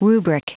Prononciation du mot rubric en anglais (fichier audio)
Prononciation du mot : rubric